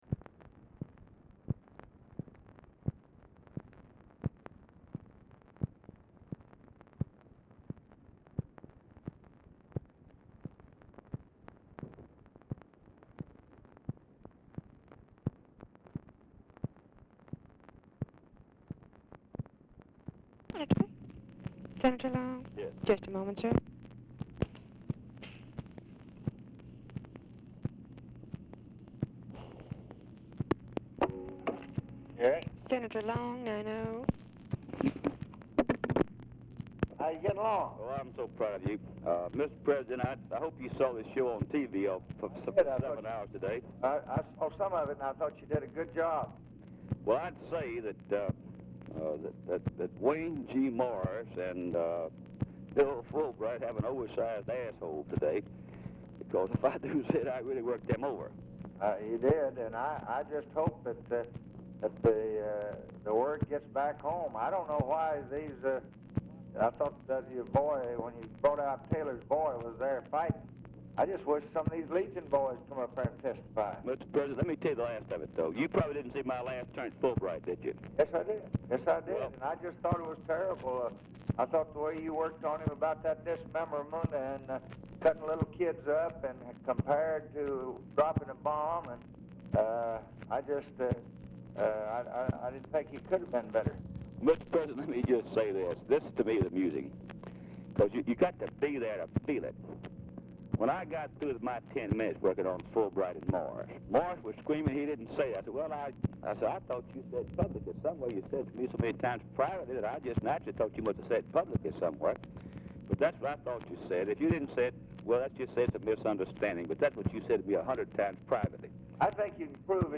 Telephone conversation # 9644, sound recording, LBJ and RUSSELL LONG, 2/17/1966, 7:35PM | Discover LBJ
Format Dictation belt